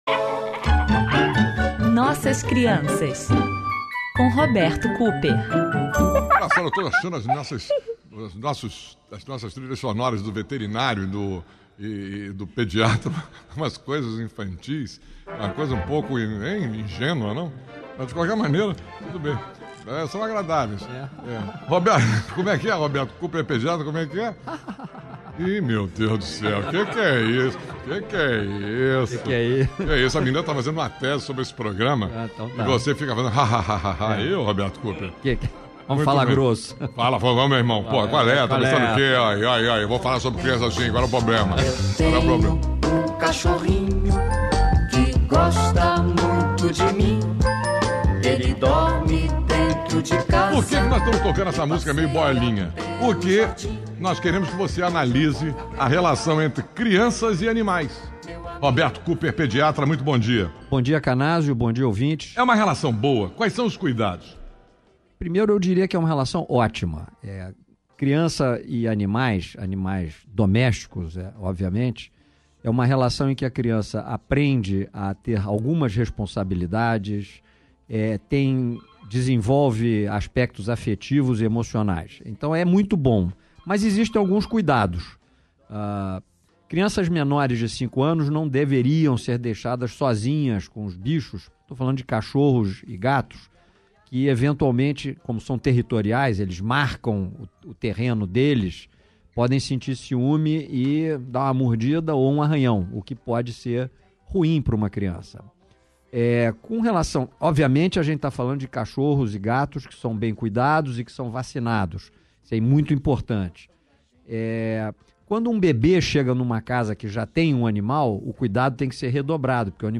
No quadro Nossas Crianças, um assunto de interesse geral é abordado. Para isso, contamos com a participação dos ouvintes da Rádio Globo e, agora, estou pedindo sugestões aos leitores do blog.